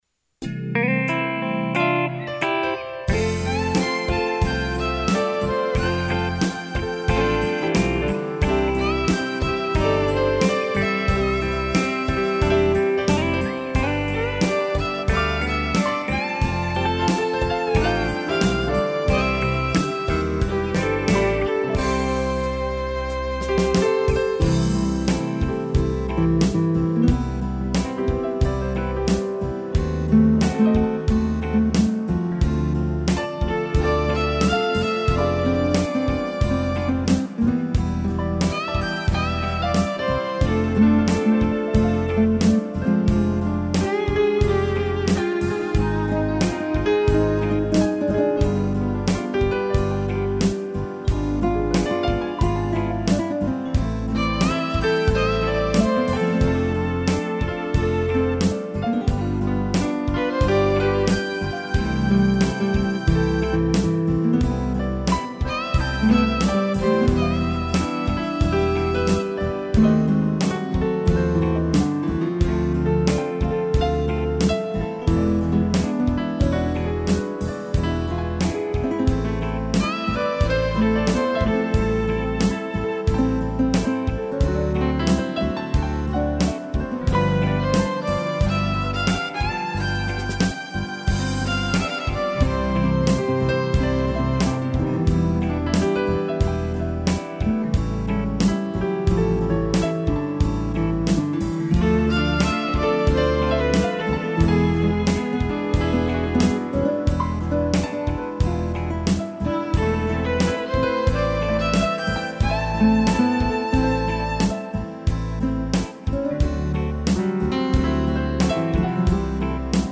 piano e violino
instrumental